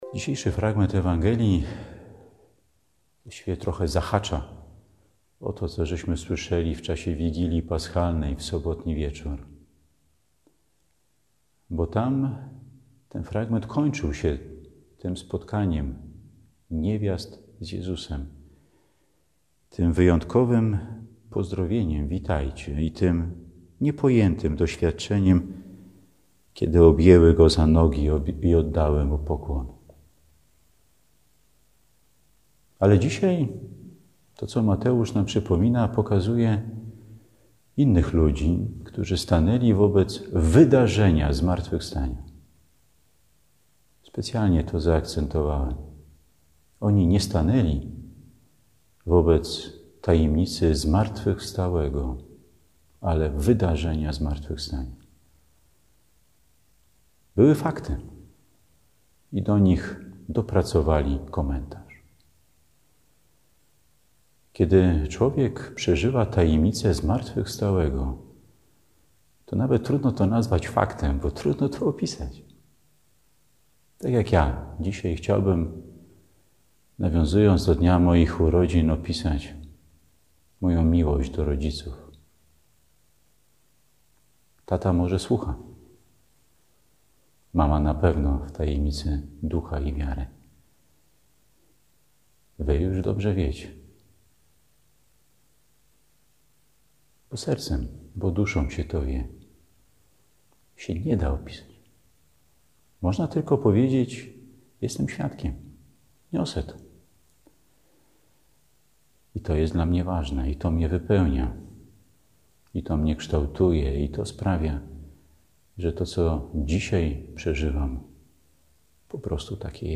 W Poniedziałek Wielkanocny biskup Marek Solarczyk przewodniczył mszy św. z kaplicy domu biskupiego na Pradze.